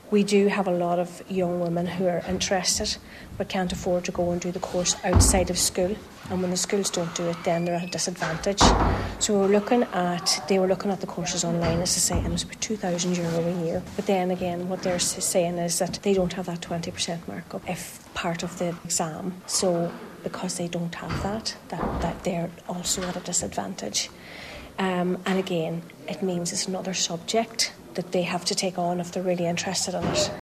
Speaking to Highland Radio Cllr Kennedy highlight some barriers to doing politics at Leaving Cert level including a €2,000 fee: